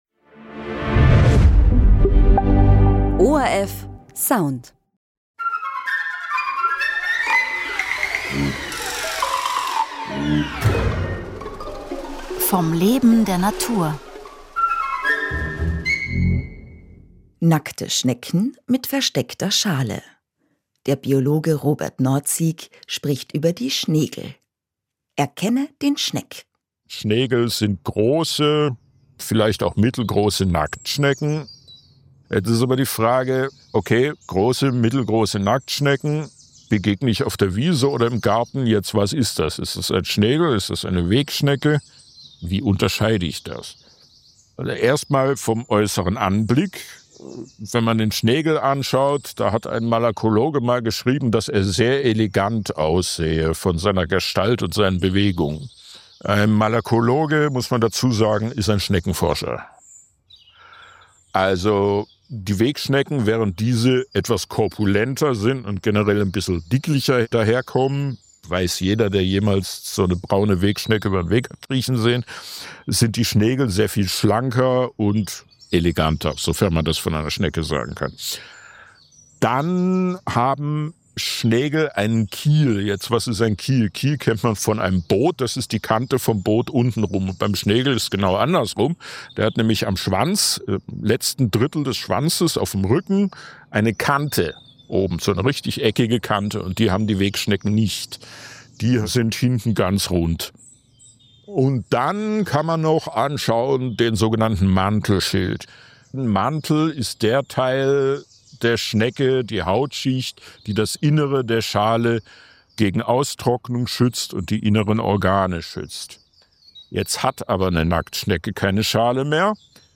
Radiointerview im ORF1 Radio